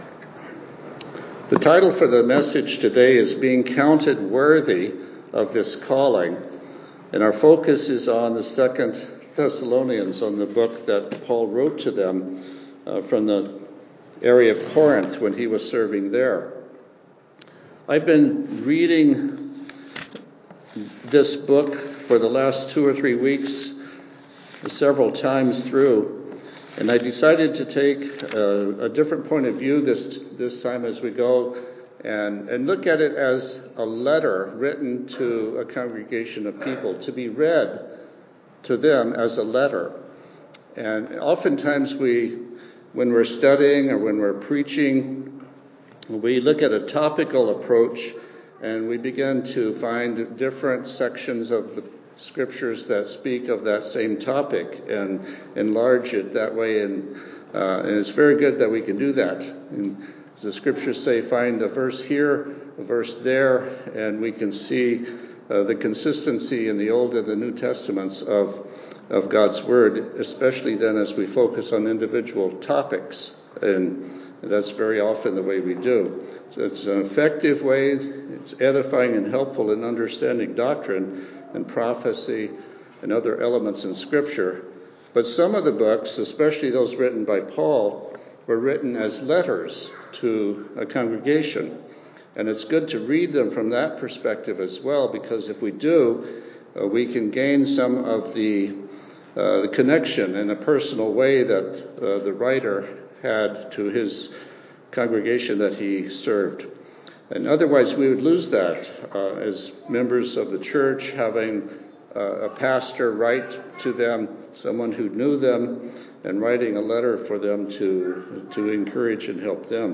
In this sermon, the approach to see in the context of the topics, the foundational truth of their spiritual calling, to help them stand fast against the deception and persecution that they were facing.